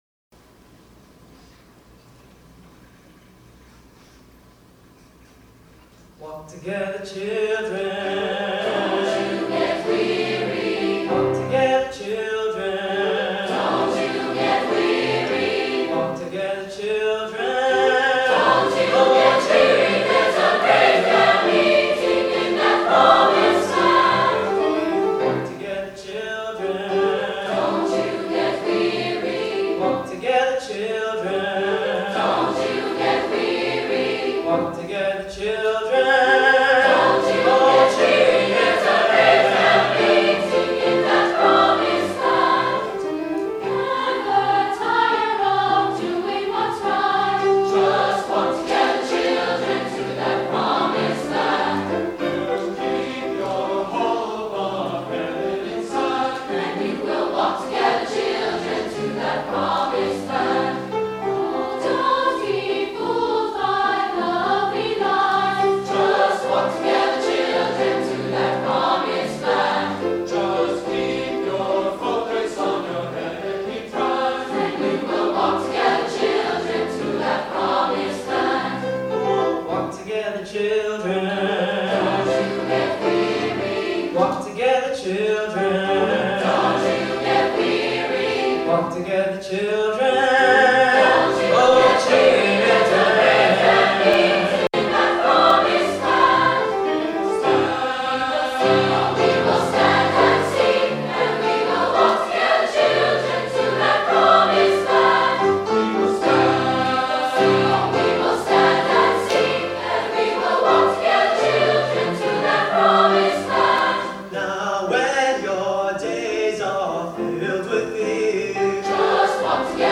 Composer: Spirituals
Voicing: 3-Part Mixed